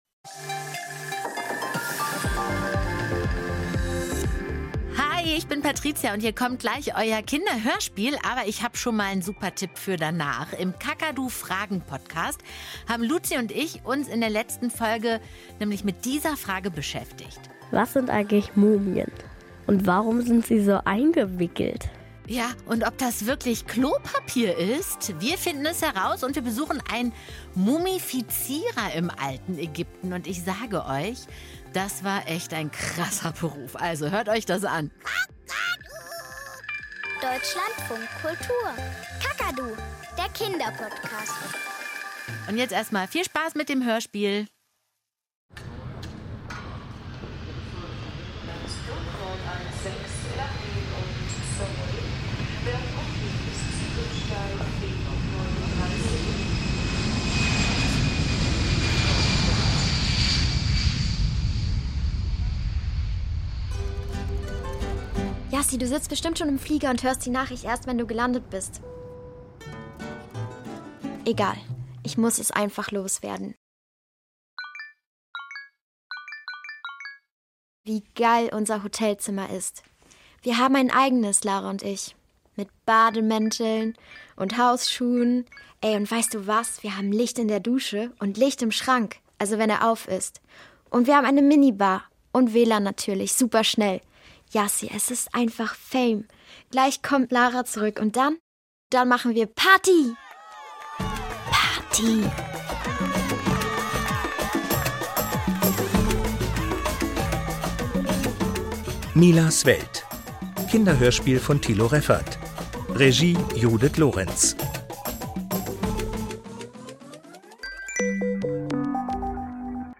Kinderhörspiel - Milas Welt ~ Kakadu – Das Kinderhörspiel Podcast